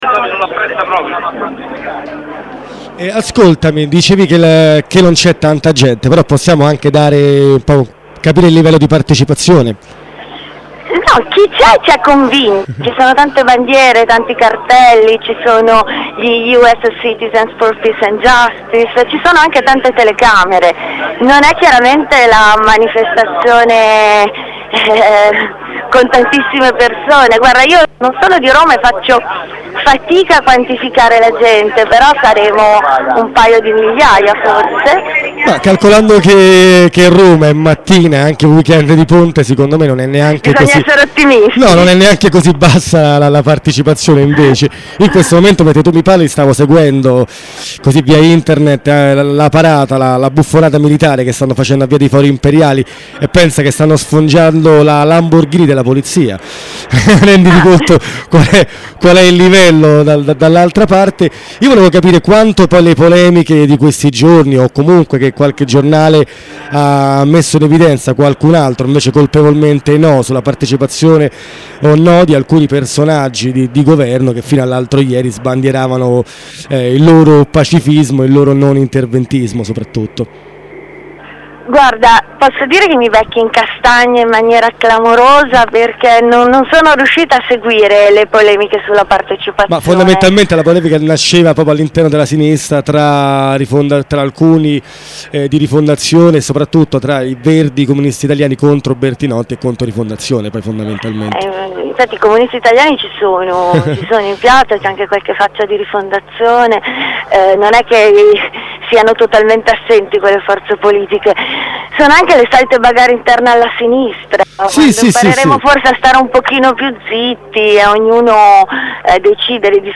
collegamento dalla piazza audio: MP3 at 11.9 mebibytes roma 12'30